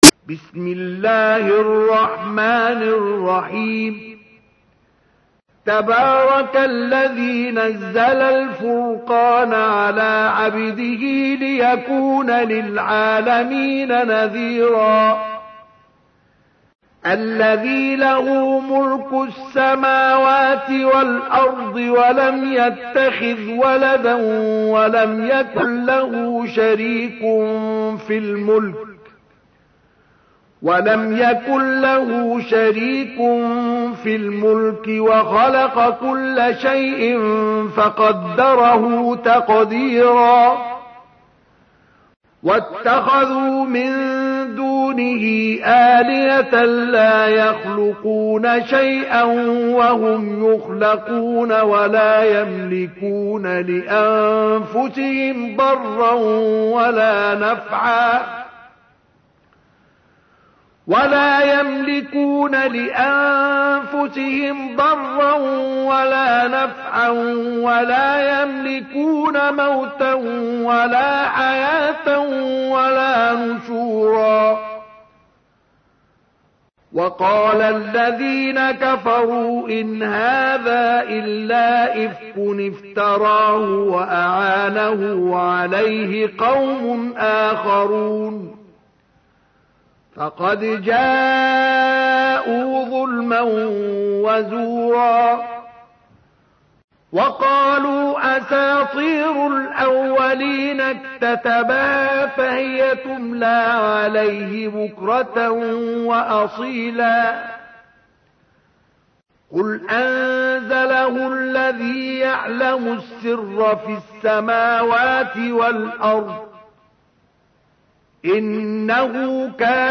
تحميل : 25. سورة الفرقان / القارئ مصطفى اسماعيل / القرآن الكريم / موقع يا حسين